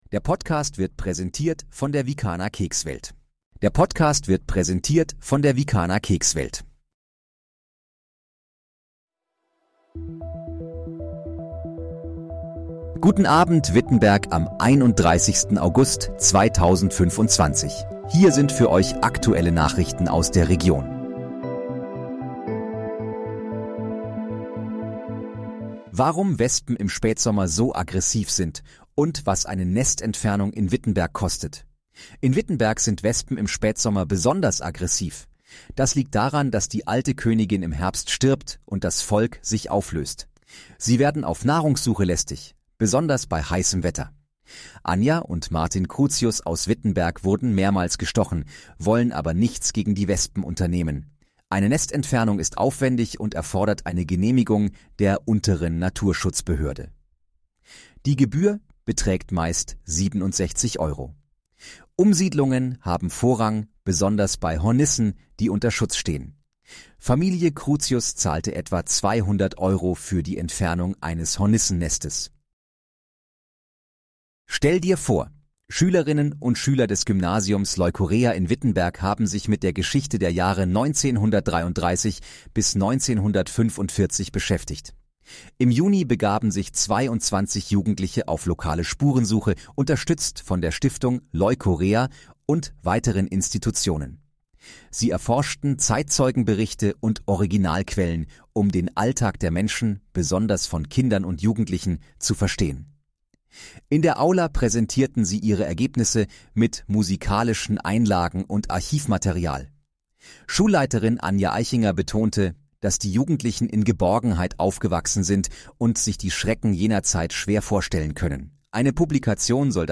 Guten Abend, Wittenberg: Aktuelle Nachrichten vom 31.08.2025, erstellt mit KI-Unterstützung
Nachrichten